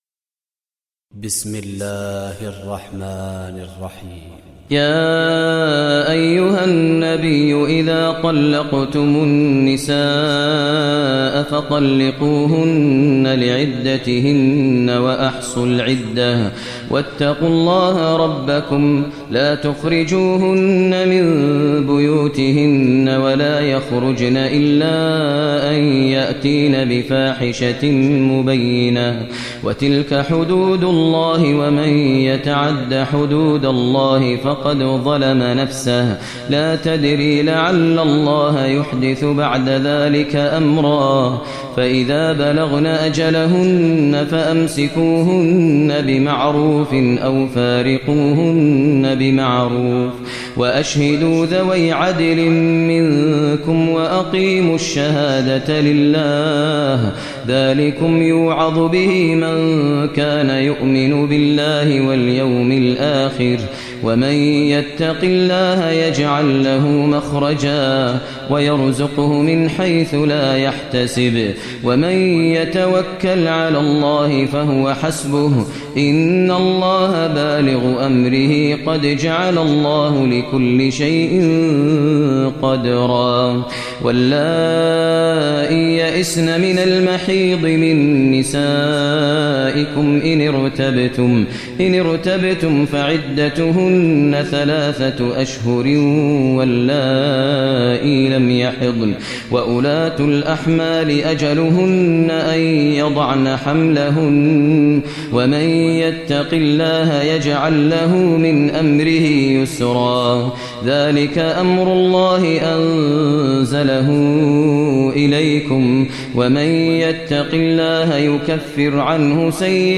Surah Talaq Recitation by Sheikh Maher al Mueaqly
Surah Talaq, listen online mp3 tilawat / recitation in Arabic recited by Imam e Kaaba Sheikh Maher al Mueaqly.